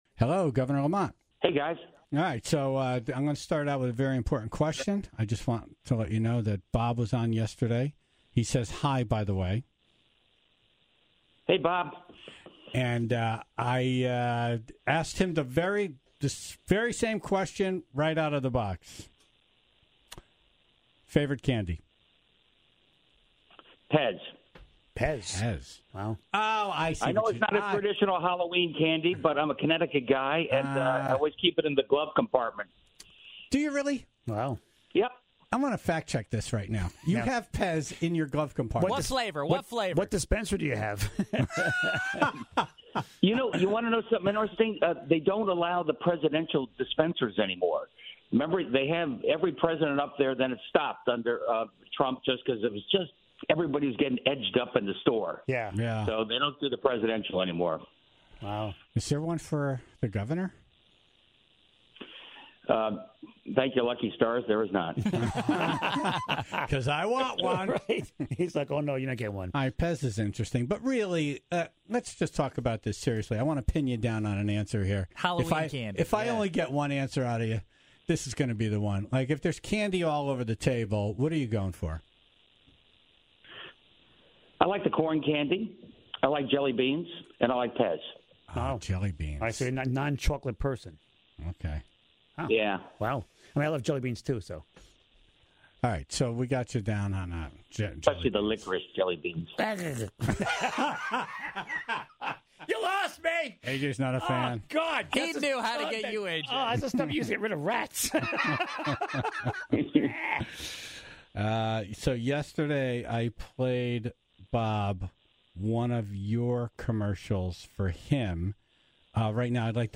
Governor Ned Lamont was on to talk about the upcoming election, and the things that are important to him this time. Plus, he had an opportunity to address a negative attack ad by the Stefanowski campaign.